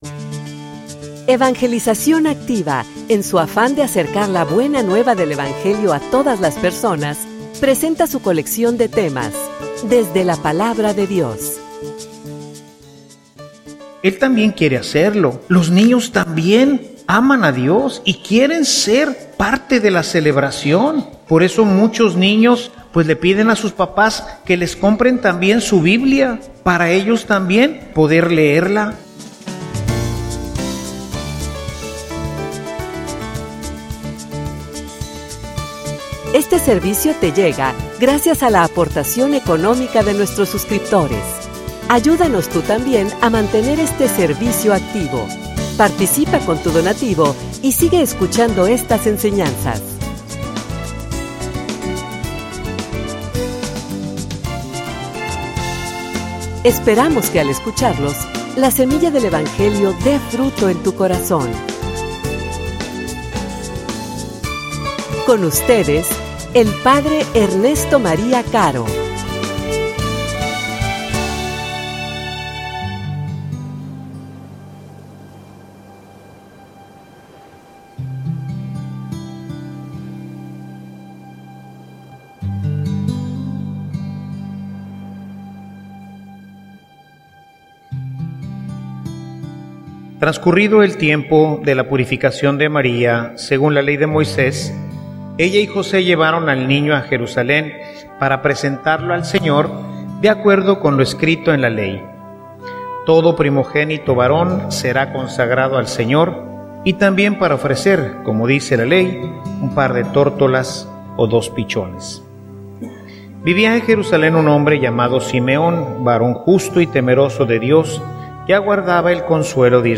homilia_Maestros_de_la_vida_cristiana.mp3